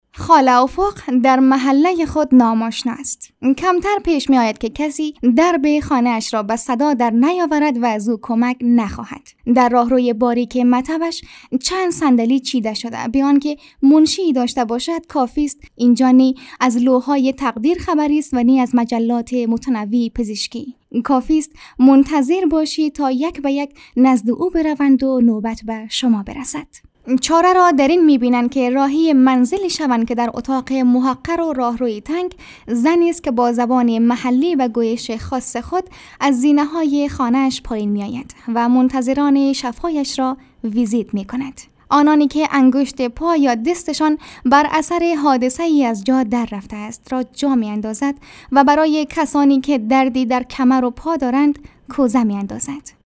Narration
Female
Young